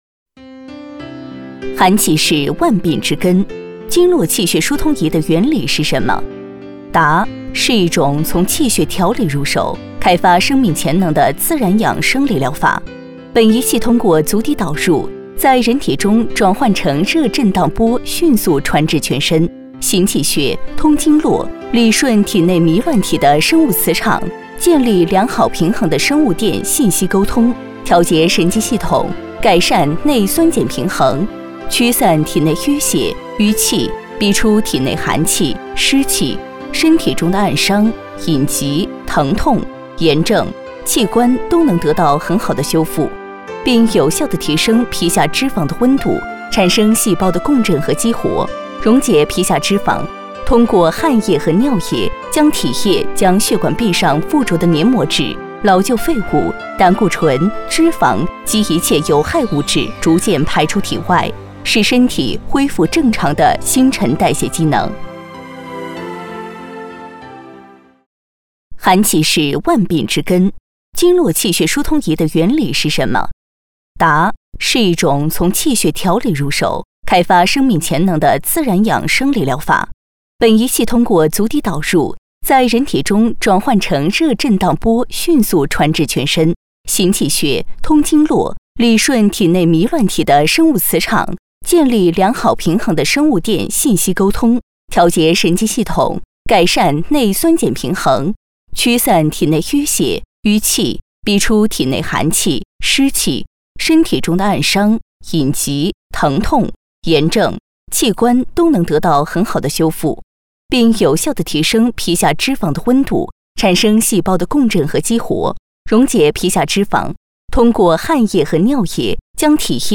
女国112_动画_角色_柏拉图的故事多角色妈妈男孩女孩-新声库配音网
配音风格： 大气 知性 时尚 稳重 亲切 轻松 年轻 活力 力度 温柔 力度 温柔